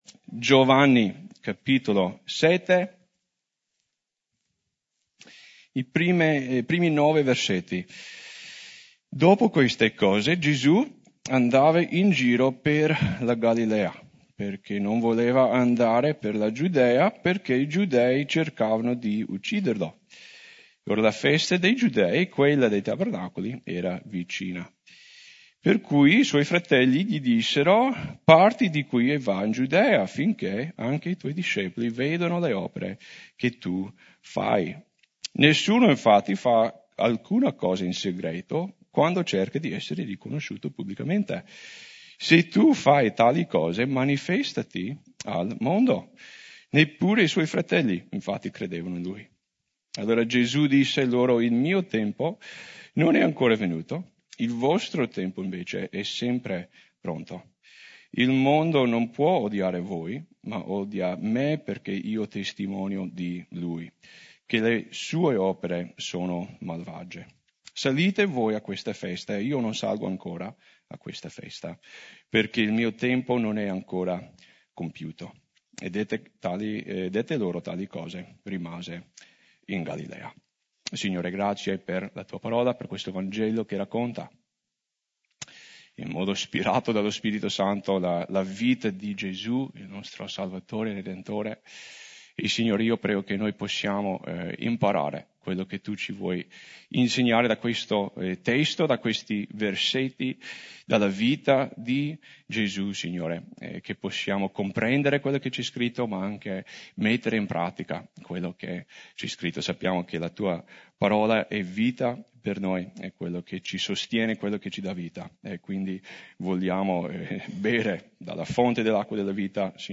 Messaggio di Domenica 22 Febbraio